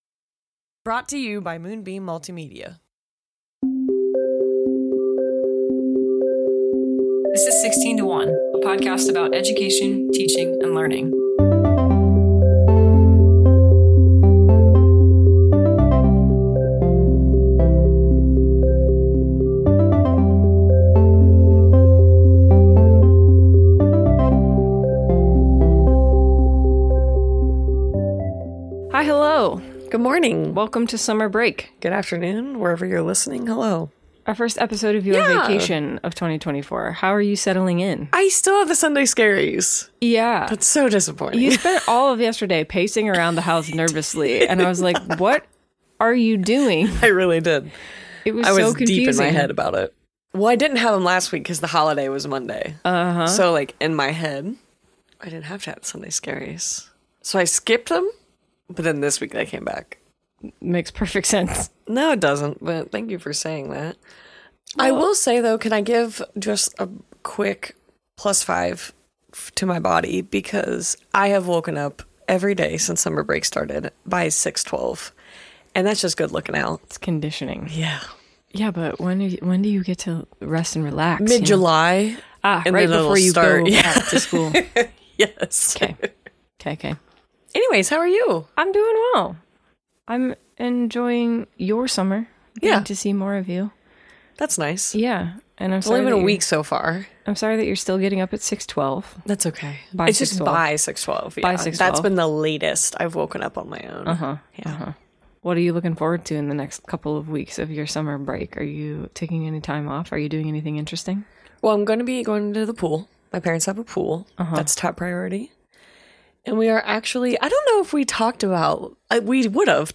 16:1 is a podcast about education, teaching, and learning. Join veteran educators for discussions about the classroom, educational psychology, policy, technology, and more.